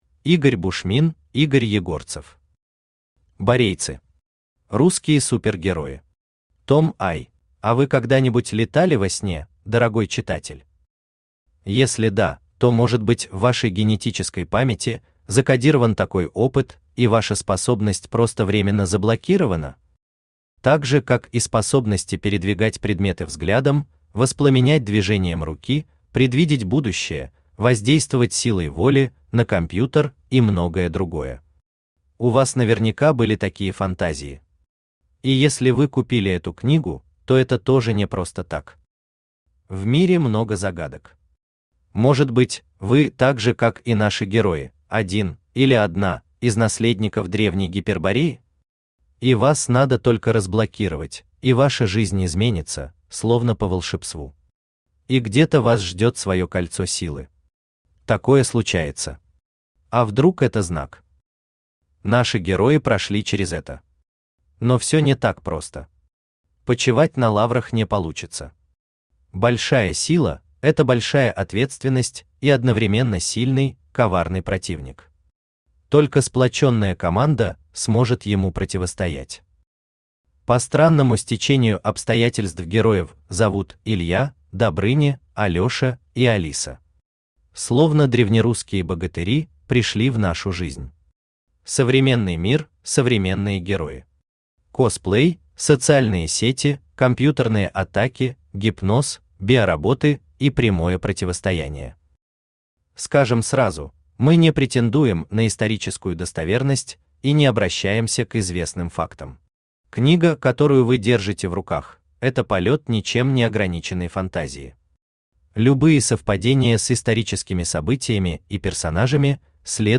Аудиокнига Борейцы. Русские Супергерои. Том I | Библиотека аудиокниг